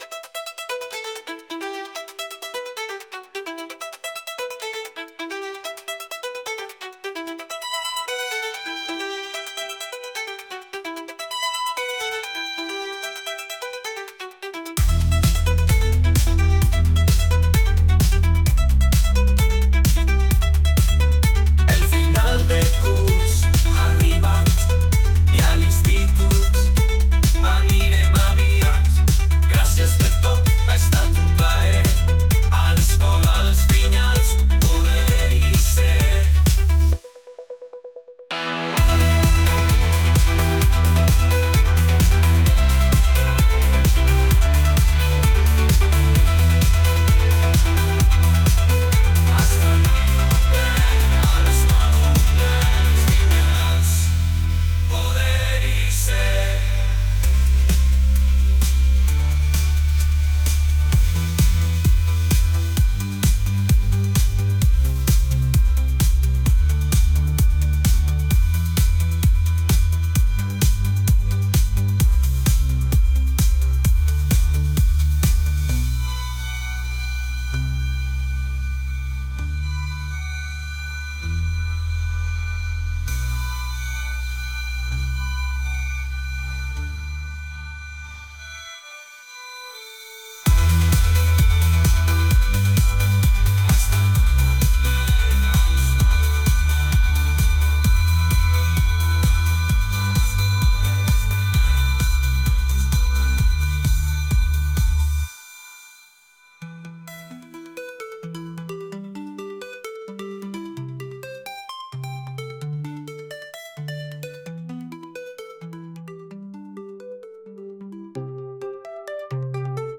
Música generada per IA